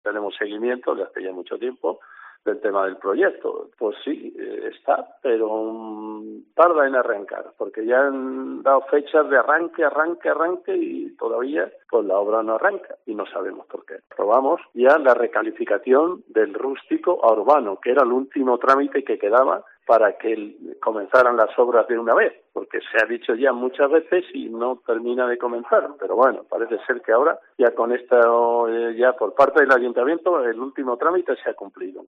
Eusebio Fernández, alcalde de Castilblanco en COPE